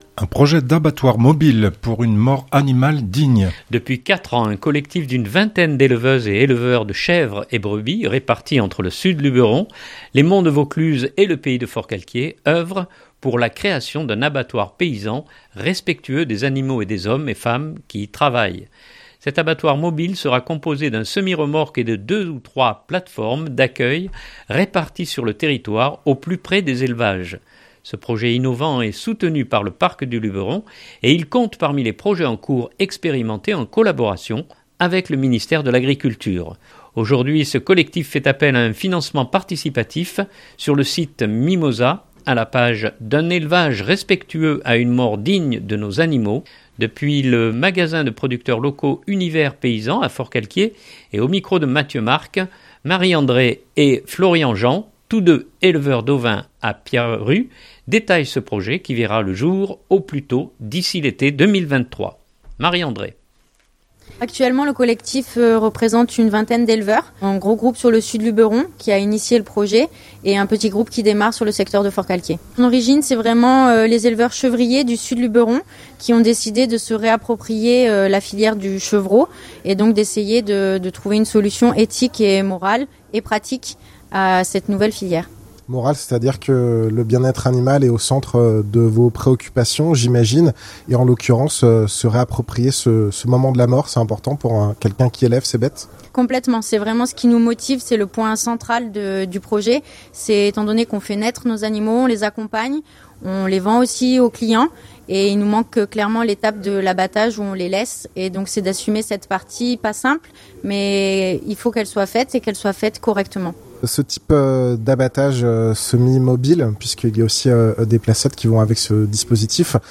Depuis le magasin de producteurs locaux Unis Verts Paysans à Forcalquier